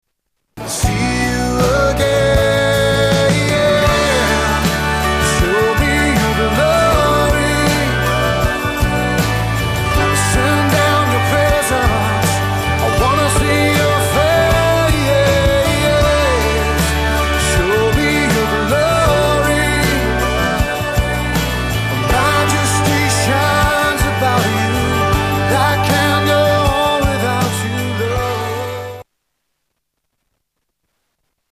STYLE: Rock
The production quality is top rate.